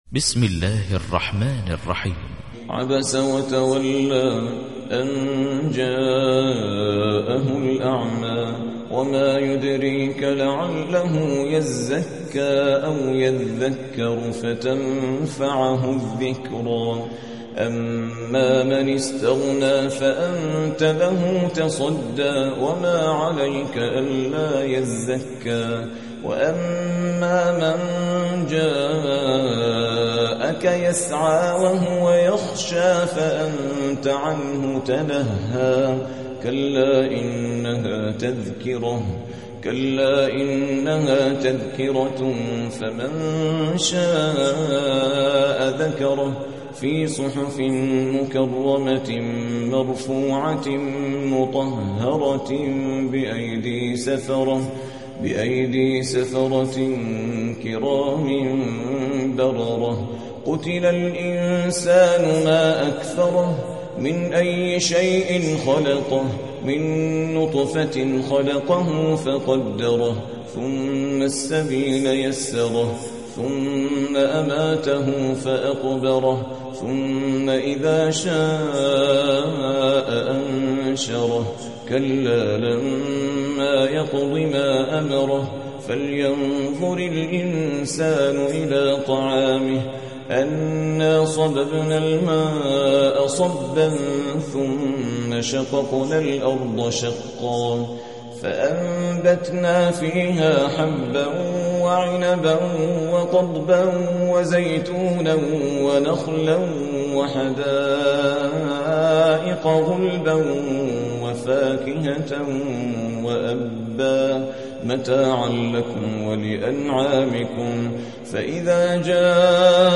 تحميل : 80. سورة عبس / القارئ عادل ريان / القرآن الكريم / موقع يا حسين